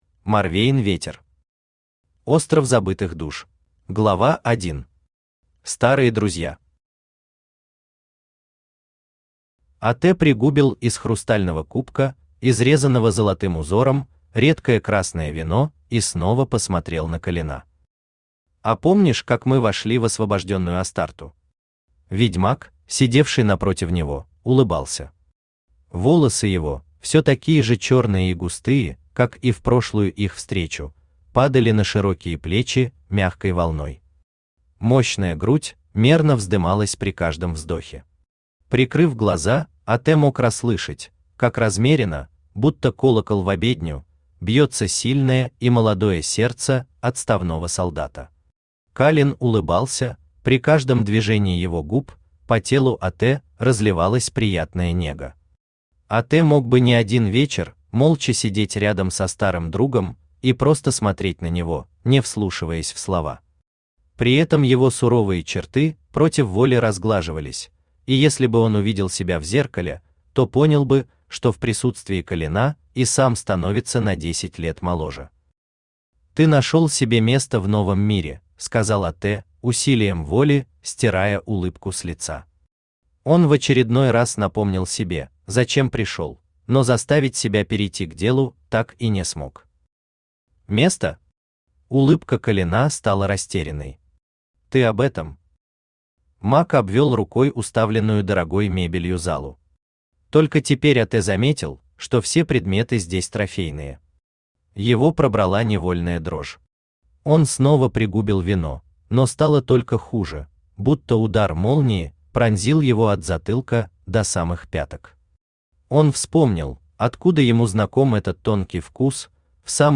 Аудиокнига Остров забытых душ | Библиотека аудиокниг
Aудиокнига Остров забытых душ Автор Морвейн Ветер Читает аудиокнигу Авточтец ЛитРес.